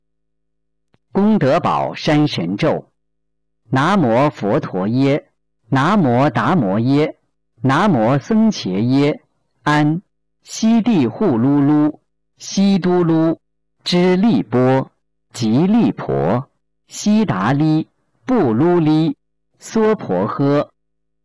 心灵净土»心灵净土 佛学书籍 经文教念.5个版本 5.佛教经文 视频：011《功德宝山神咒》男声
011《功德宝山神咒》男声